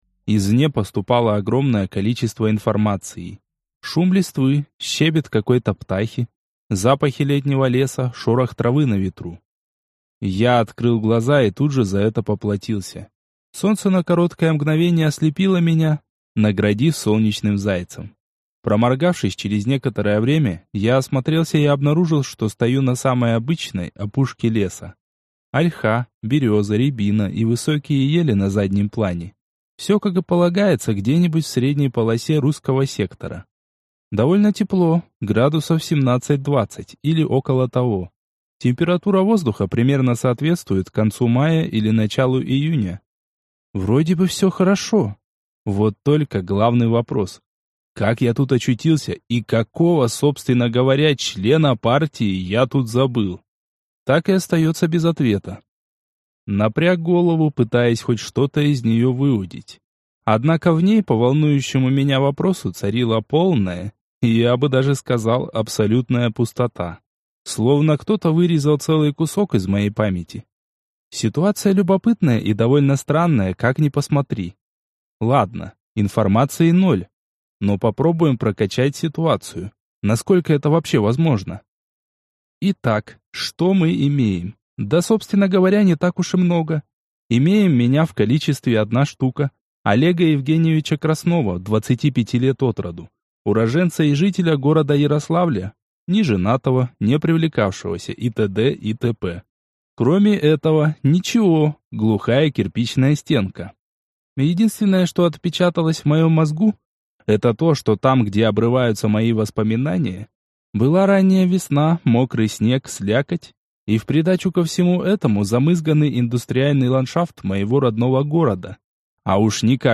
Аудиокнига Gamemaster | Библиотека аудиокниг